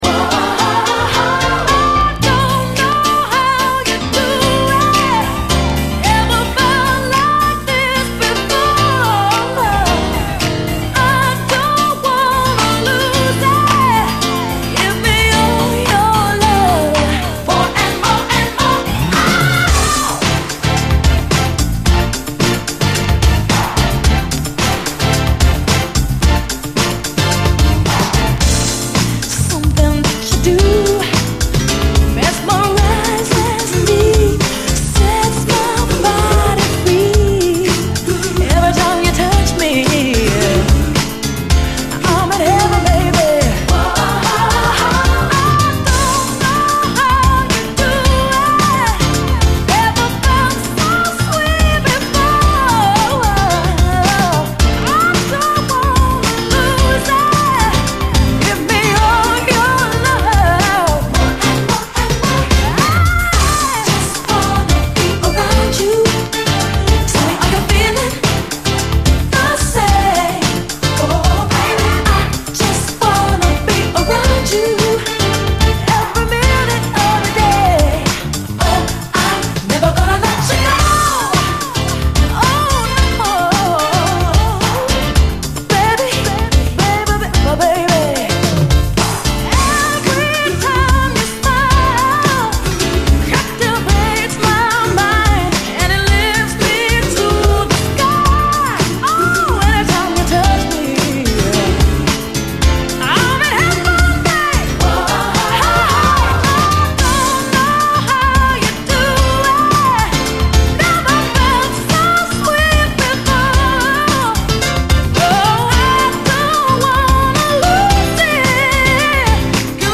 中盤のブレイク以降のピアノも美しいので試聴ファイルは長めです。